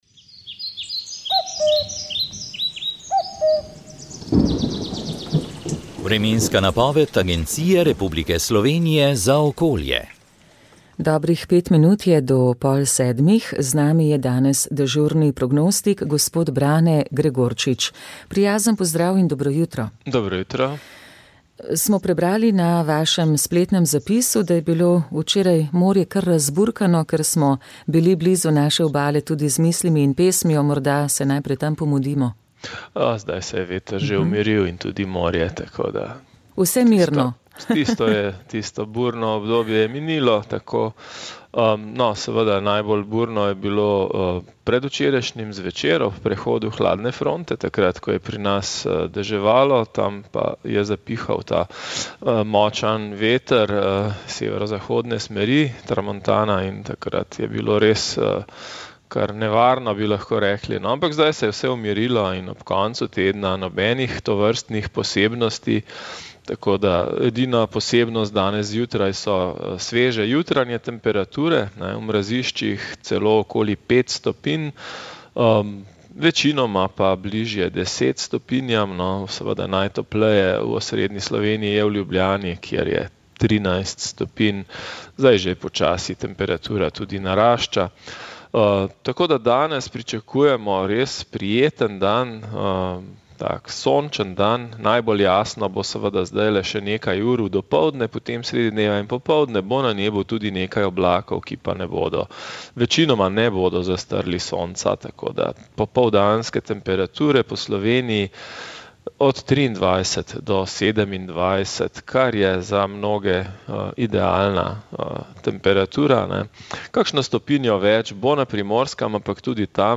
Vremenska napoved 09. julij 2022